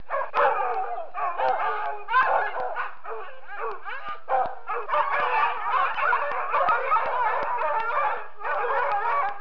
دانلود صدای حیوانات جنگلی 46 از ساعد نیوز با لینک مستقیم و کیفیت بالا
جلوه های صوتی